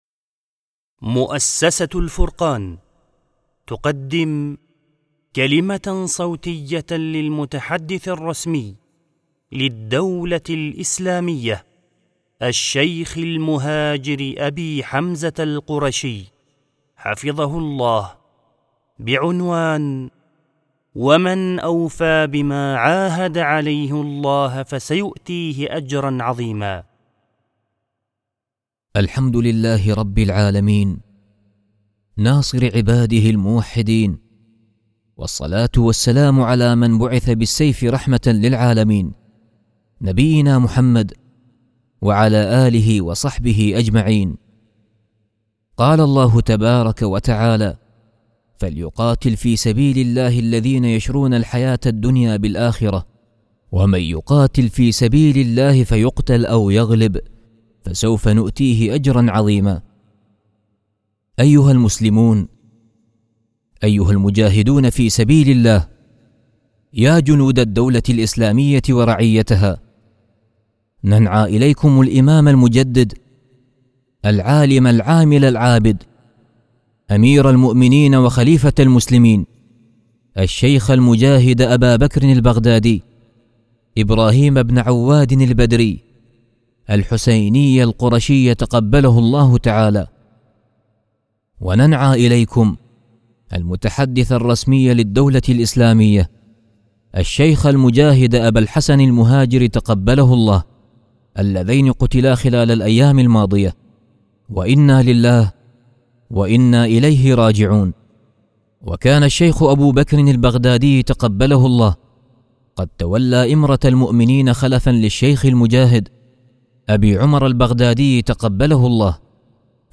18:17: Вышло новое обращение от аль-Фуркан. Слово держит Абу Хамза аль-Курейши, новый пресс-секретарь ИГ.